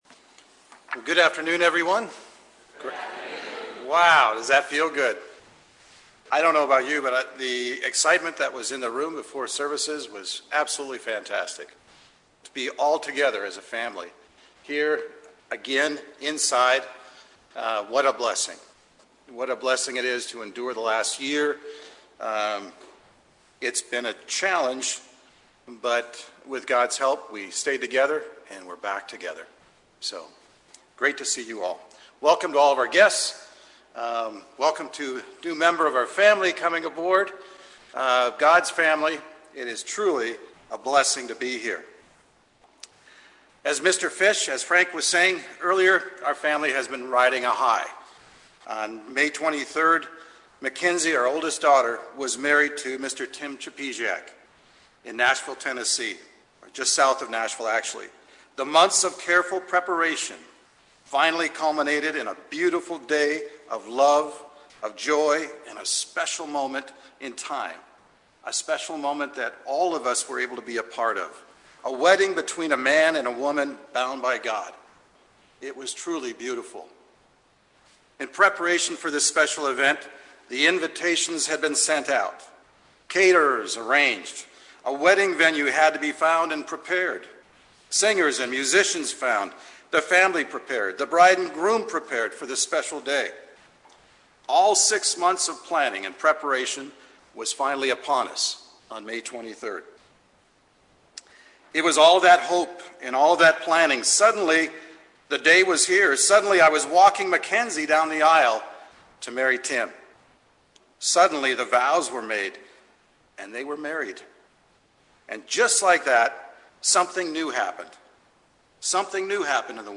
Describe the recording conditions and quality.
Given in Bakersfield, CA Los Angeles, CA